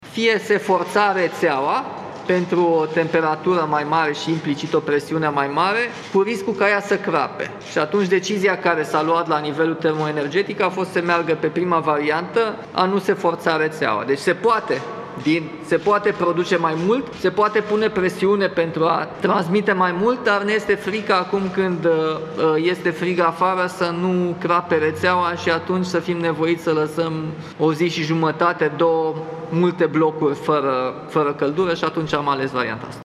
”În acest moment există doar două avarii minore pe rețeaua secundară, care vor fi remediate până azi, în cursul după-amiezii”, a declarat Primarul General Nicușor Dan, într-o conferință de presă.